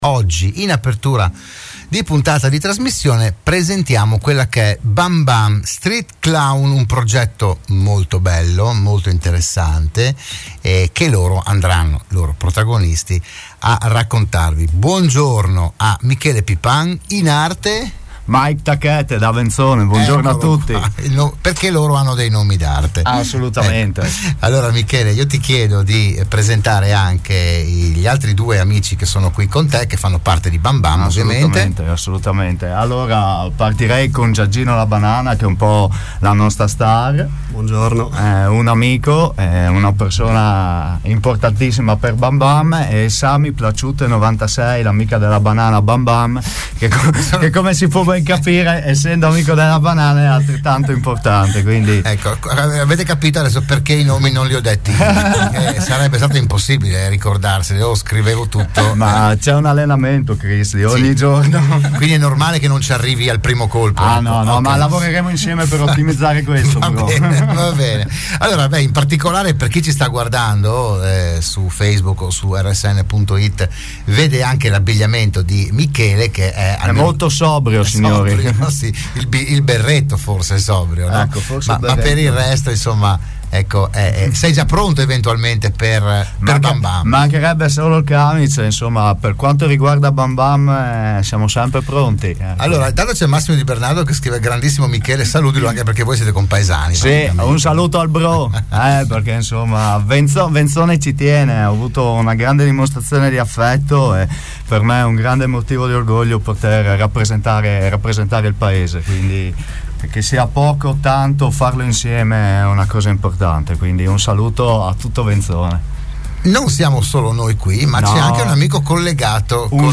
ha ospitato oggi i rappresentanti del Bamban Street Clown (nella foto).